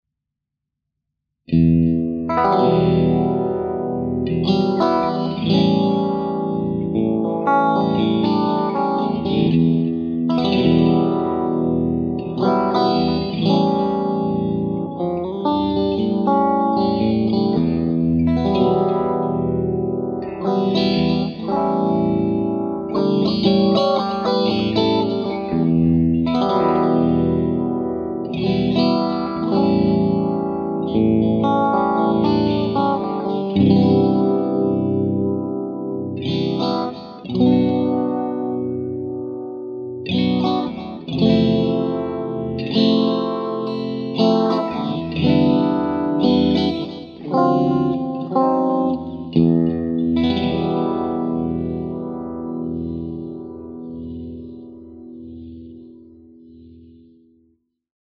Il FANE ASCENSION F70 è caratterizzato da un sound presente e dettagliato sulle frequenze alte, caldo e rotondo nelle frequenze medio-basse, ricco di armoniche e con una spazialità notevole che regala una grande profondità al suono.
Tutti i sample (che trovate anche nel video) sono stati registrati con:
Cab 1×12 semi open back
AKG dynamic mic off axis (cap edge)
CLEAN
Black Strat > Compulator > Univibe > Delay > HIWATT T40 > CAB FANE F70